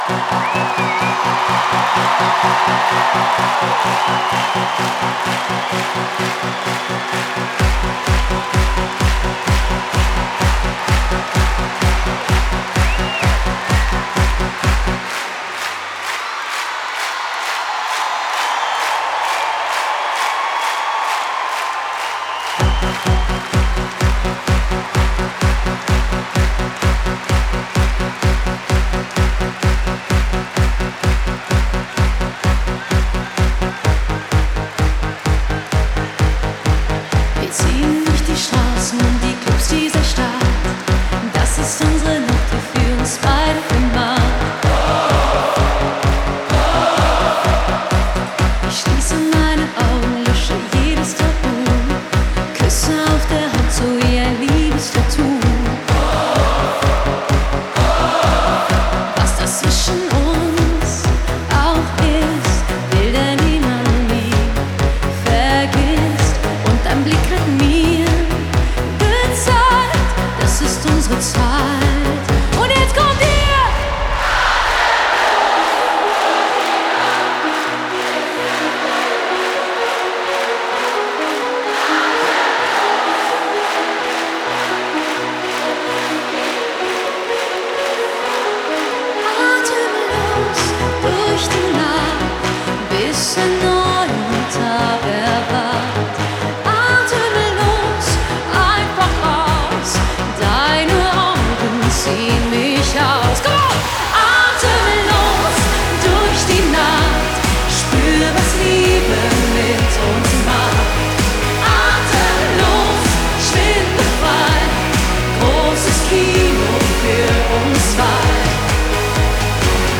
Germany • Genre: Pop • Style: Schlager